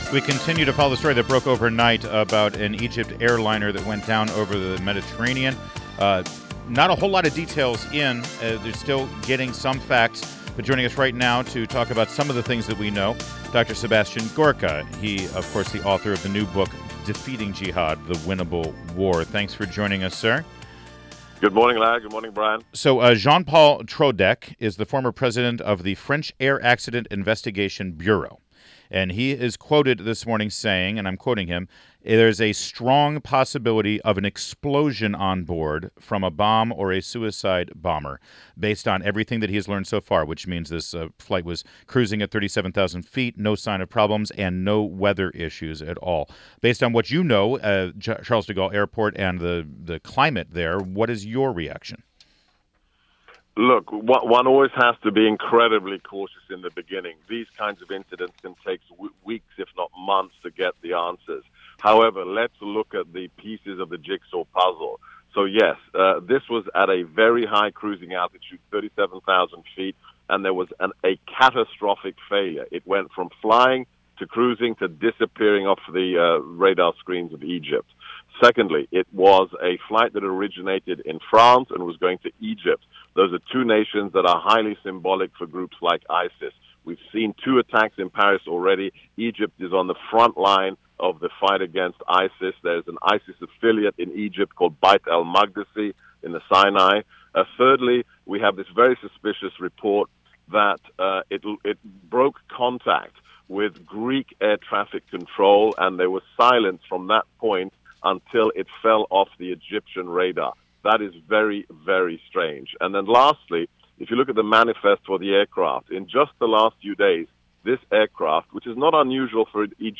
WMAL Interview: SEBASTIAN GORKA 05.19.16